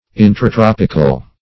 Intratropical \In`tra*trop"ic*al\, a.